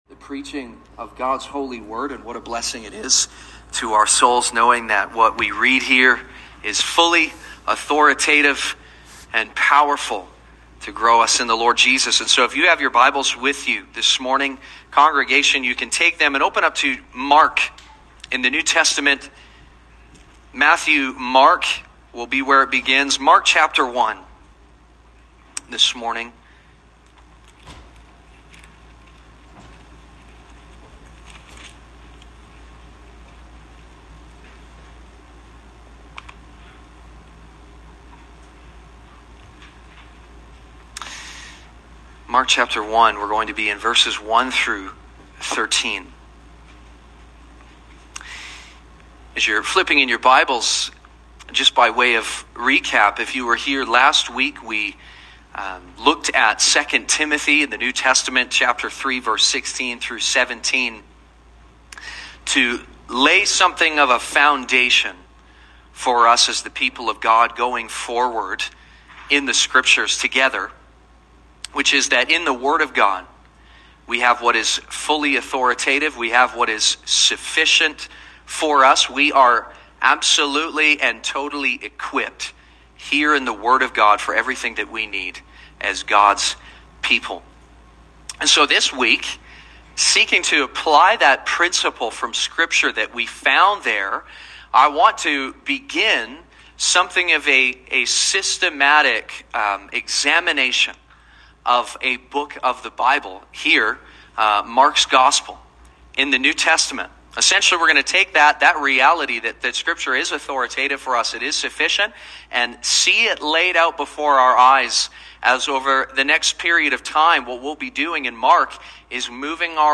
Sermons | Sonrise Community Baptist